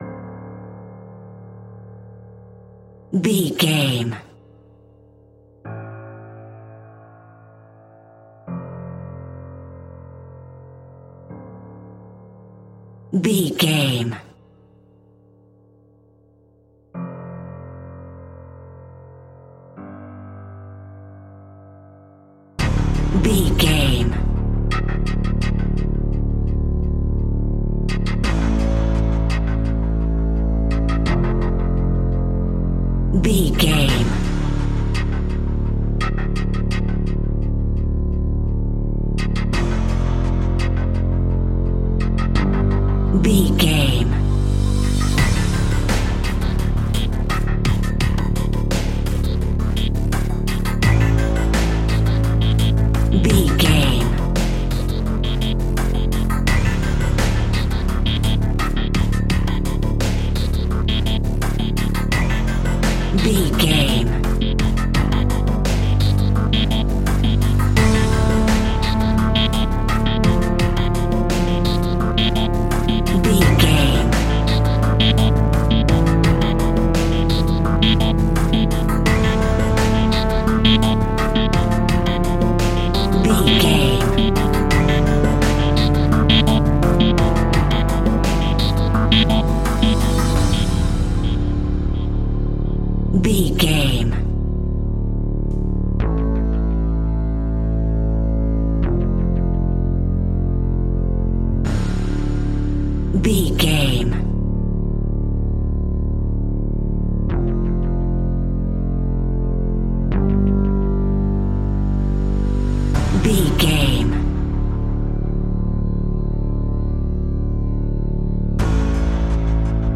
Aeolian/Minor
D
tension
ominous
eerie
piano
strings
drums
synthesiser
ticking
electronic music